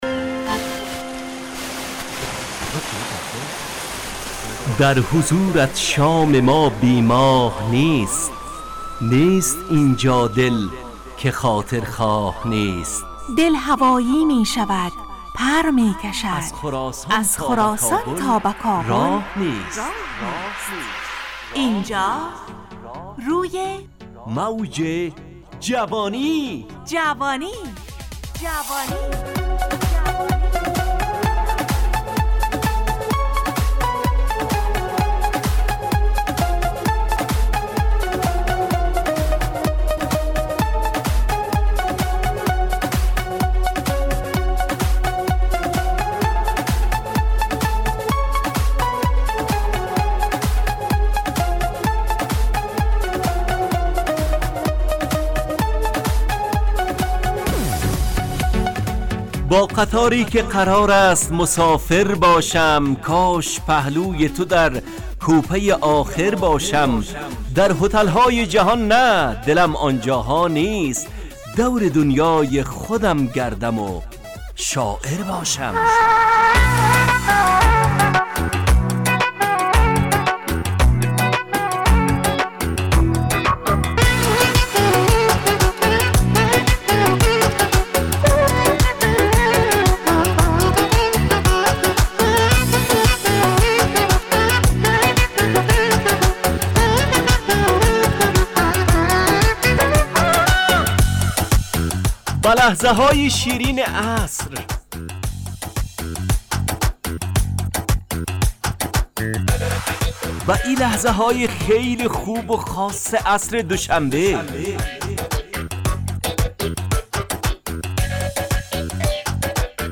روی موج جوانی، برنامه شادو عصرانه رادیودری.
همراه با ترانه و موسیقی مدت برنامه 70 دقیقه . بحث محوری این هفته (مسجد) تهیه کننده